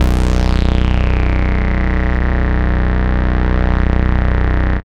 MANIAK BASS.wav